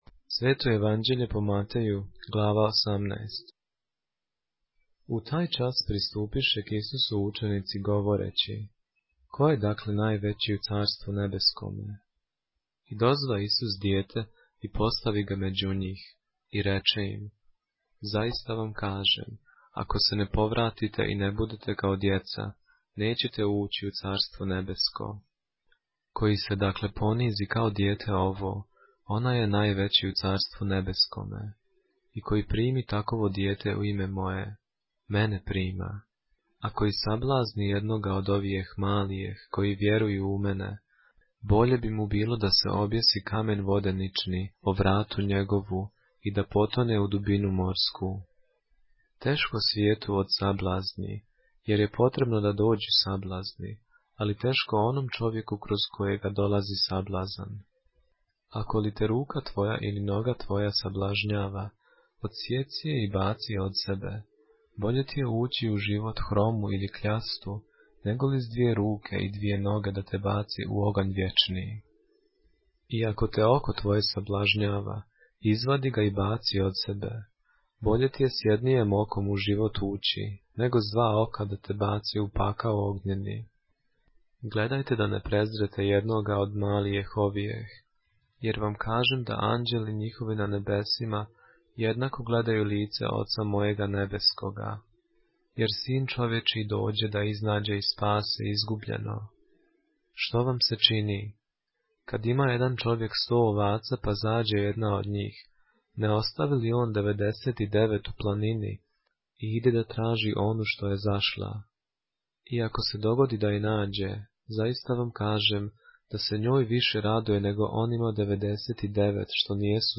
поглавље српске Библије - са аудио нарације - Matthew, chapter 18 of the Holy Bible in the Serbian language